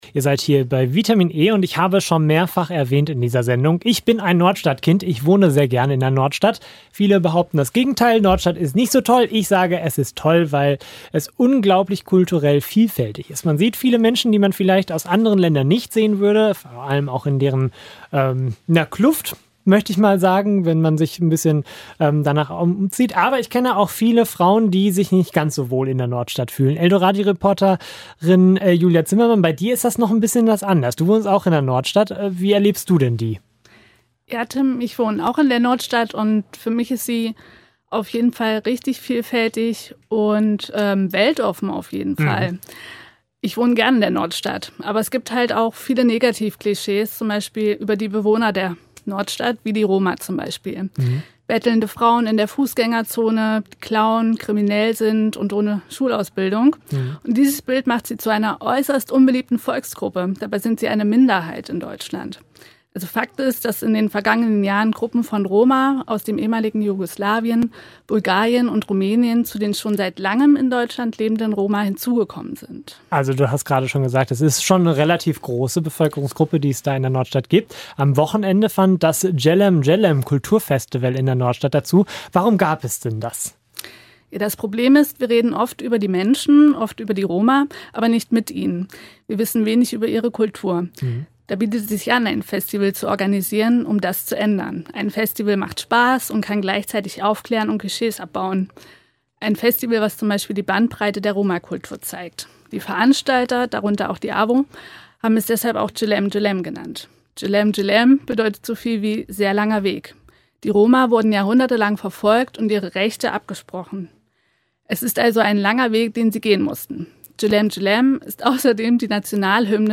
Serie: Kollegengespräch  Sendung: Vitamin e*